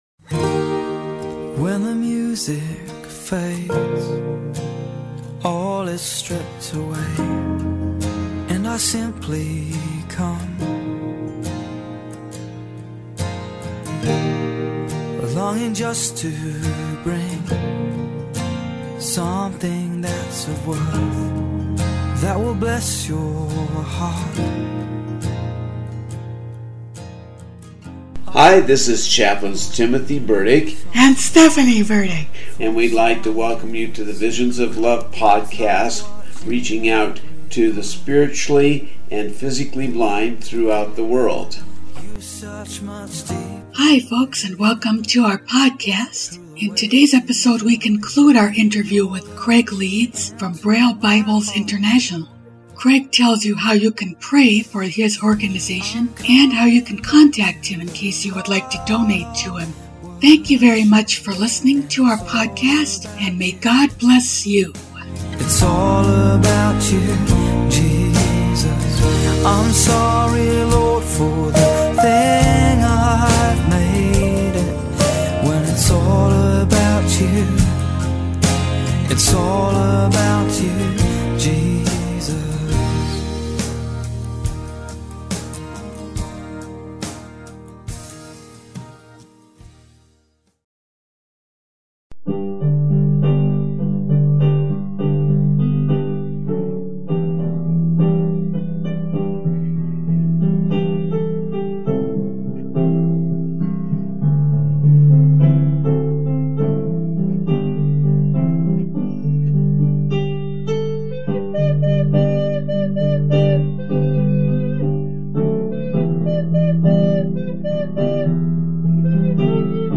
we conclude our interview